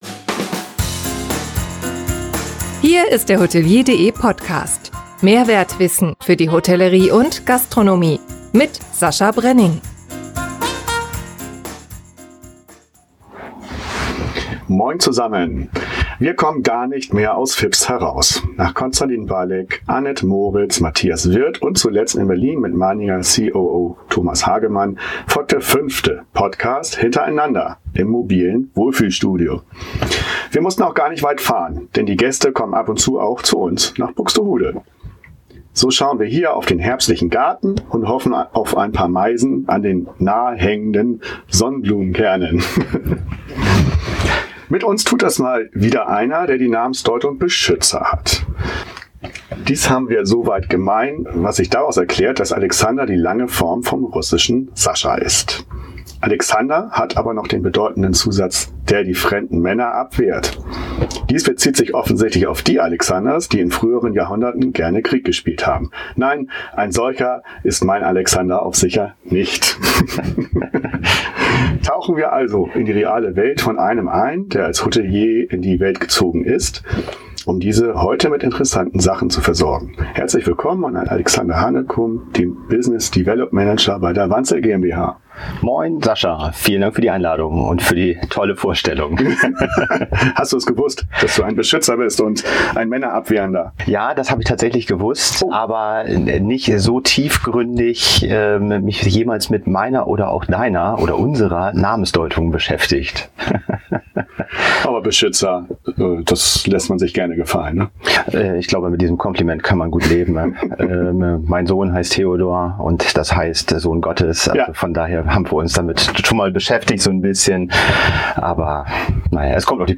Und dies im Wohnmobil Fips in Buxtehude!